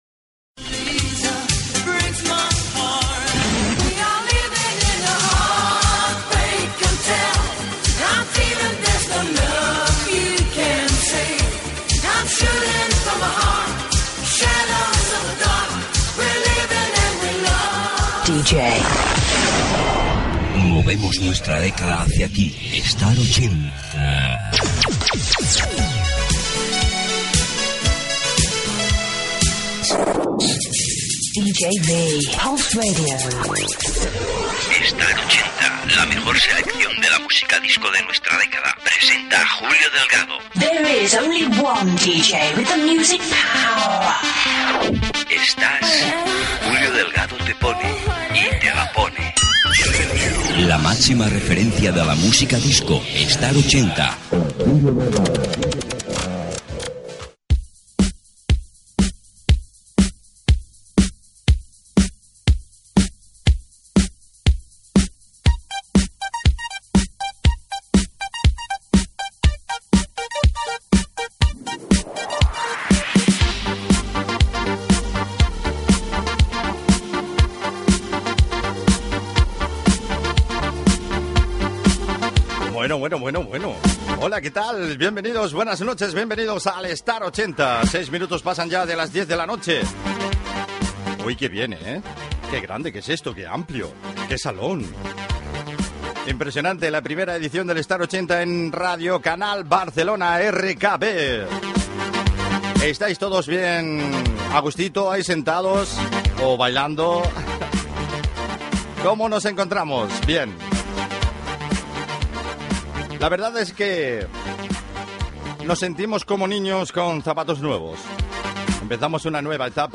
Careta del programa, hora, presentació de la primera edició del programa en aquesta emissora
Musical